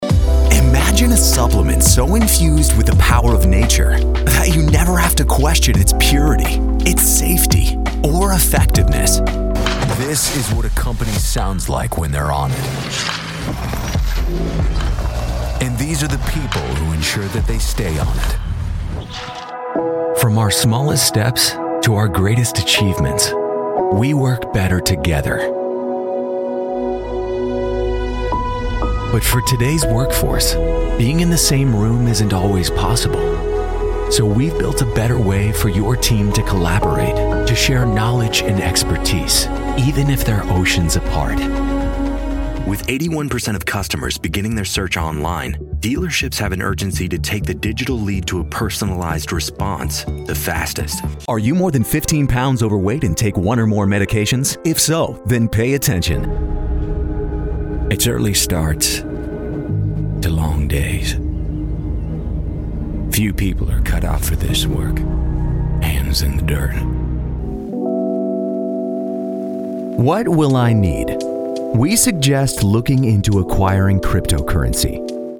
Natürlich, Unverwechselbar, Zugänglich, Vielseitig, Warm
Erklärvideo